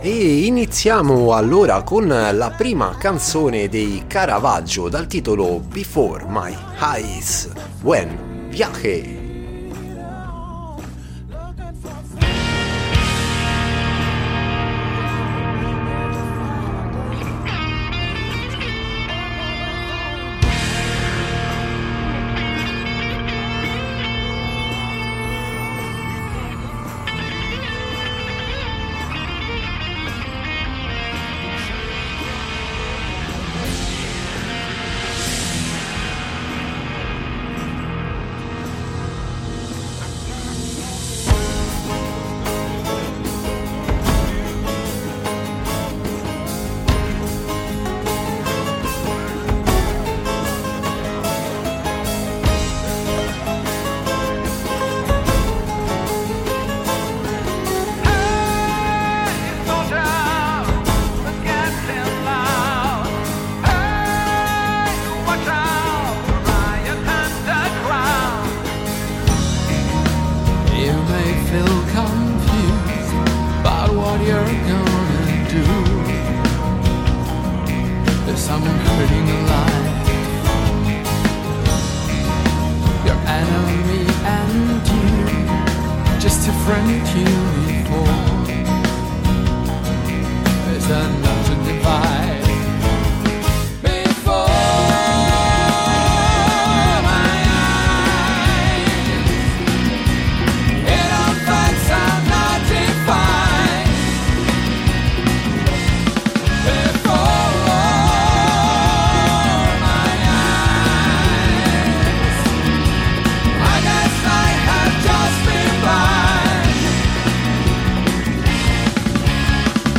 PROGRESSIVE ROCK
Abbiamo chiacchierato e ascoltato la musica di questo gruppo di Milano uscito in estate con un album tutto da scoprire!